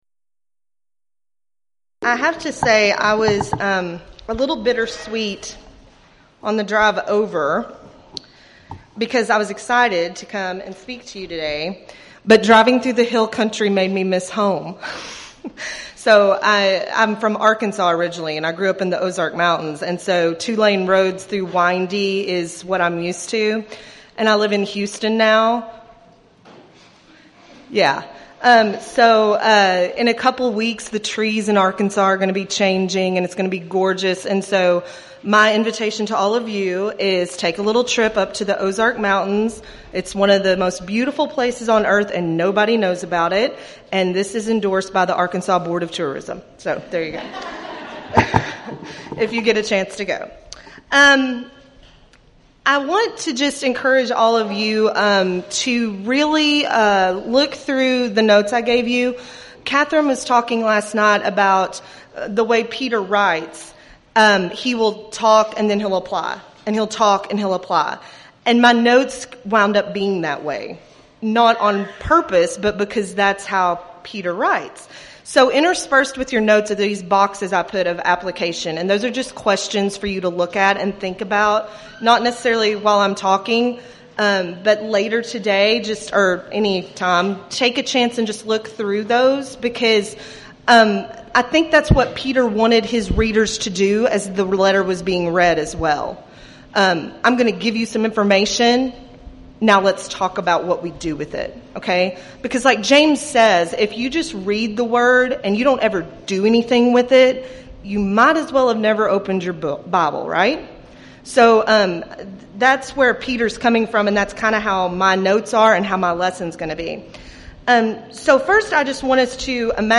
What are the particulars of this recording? Event: 3rd Annual Texas Ladies in Christ Retreat Theme/Title: Studies in I Peter